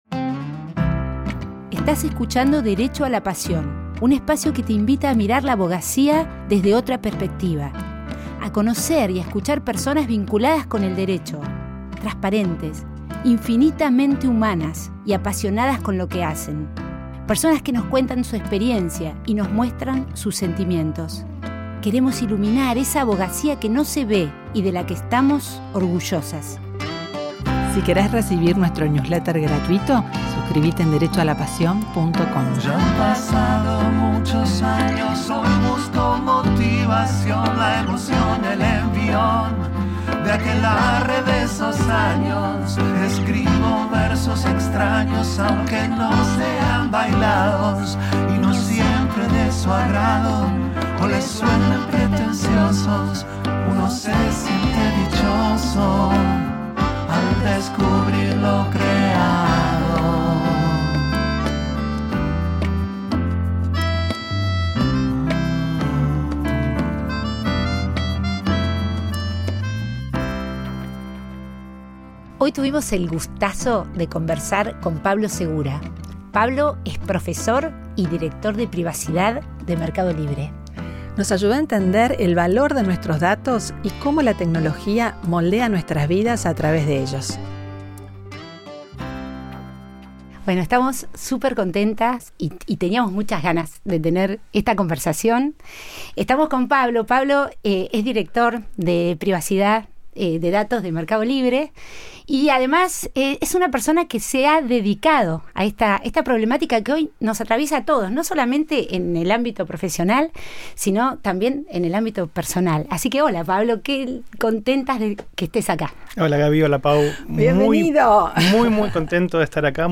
Tuvimos una conversación espectacular